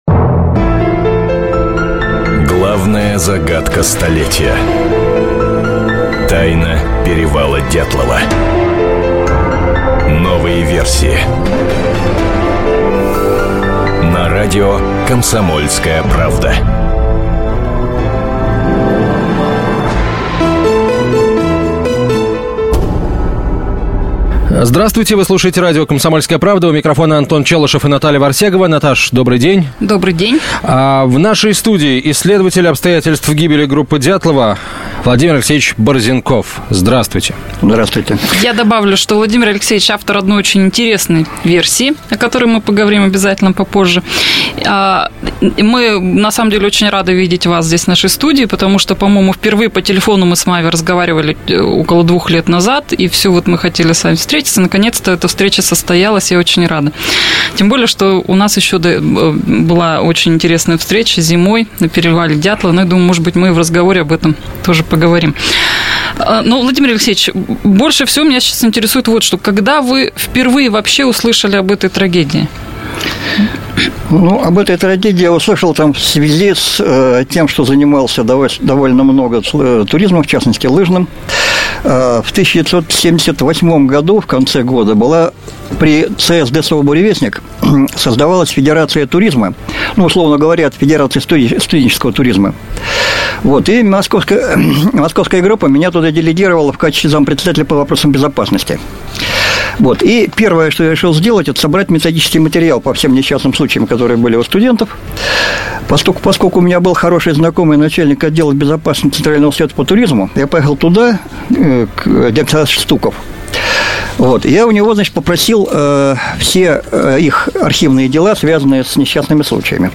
Аудиокнига Инфразвуковая версия гибели группы Дятлова | Библиотека аудиокниг